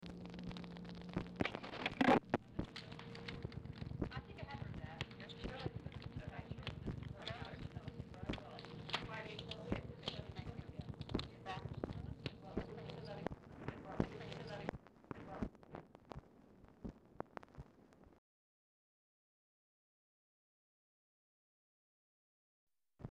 Telephone conversation # 5544, sound recording, OFFICE CONVERSATION, 9/9/1964, time unknown | Discover LBJ
Format Dictation belt
Location Of Speaker 1 Oval Office or unknown location